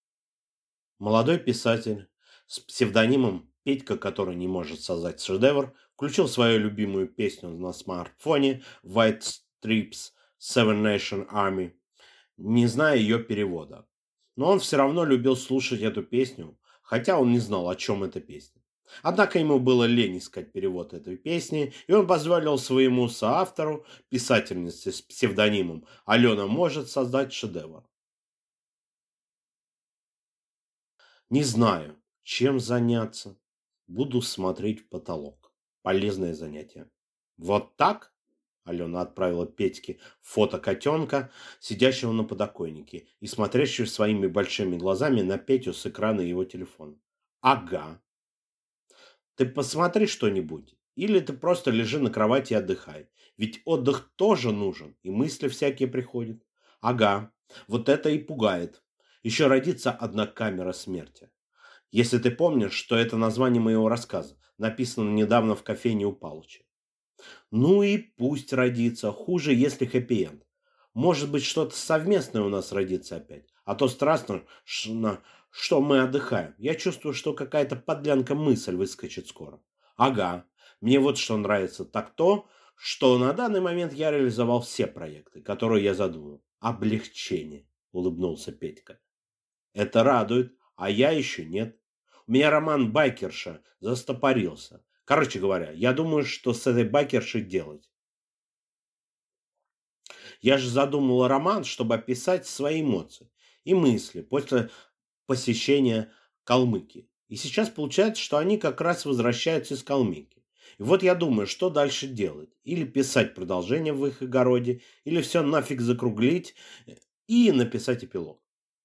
Аудиокнига Как мы создали шедевр | Библиотека аудиокниг